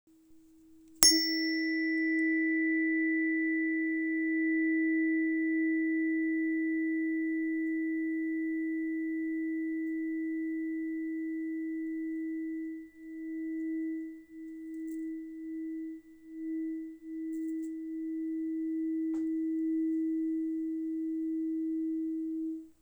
🌊 Aluminum therapeutic tuning fork 319.88 Hz – Kidneys
Its soft, deeper tone has a calming effect and helps to tune into the natural rhythm of the body, restore confidence in life and replenish energy after mental and physical stress.
• Material: aluminum
• Frequency: 319.88 Hz (E♭)
• A gentle, pure reverberation for deep relaxation and meditation.